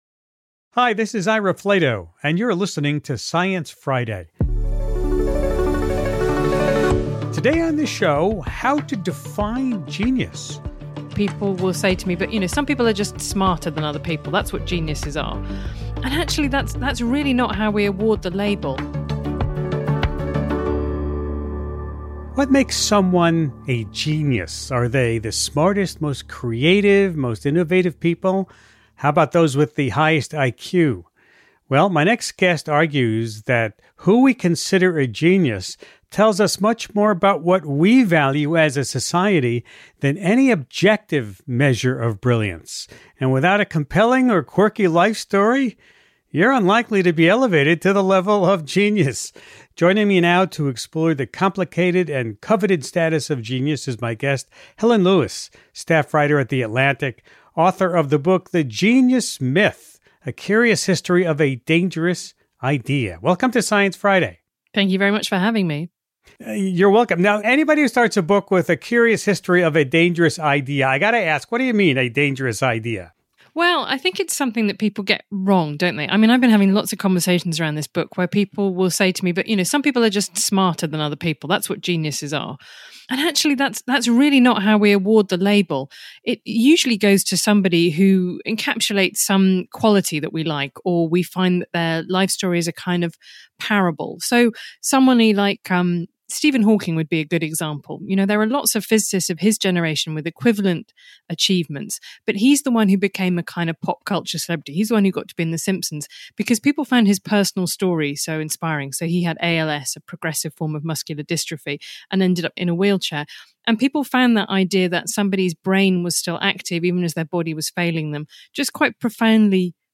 Host Ira Flatow unpacks the complicated and coveted title of genius with Helen Lewis, author of T he Genius Myth: A Curious History of A Dangerous Idea .